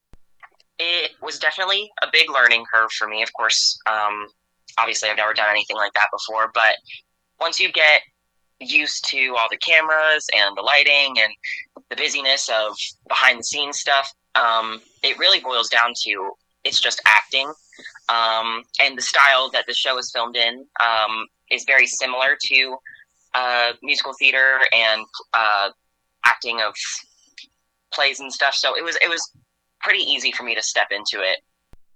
talked with us about the differences between working on stage and working on a TV show.